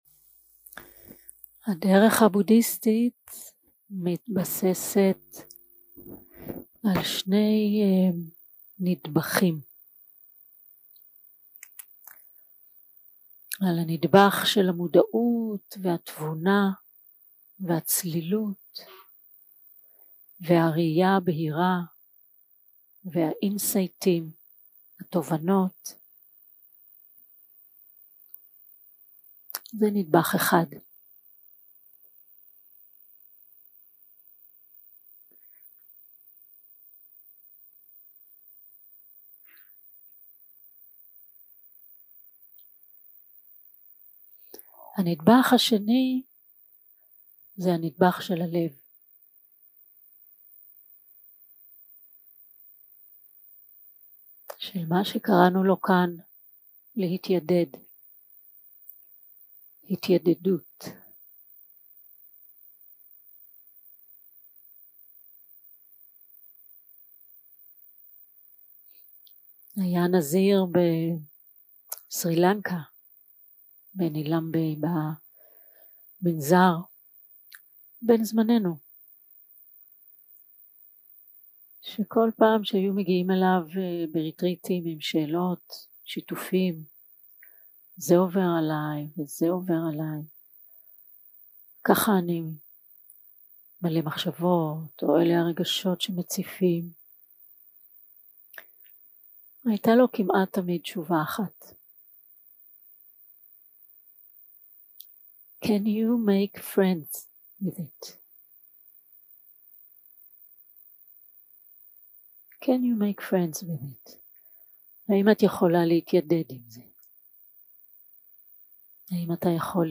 יום 3 - הקלטה 6 - צהרים - מדיטציה מונחית - מטא
Guided meditation שפת ההקלטה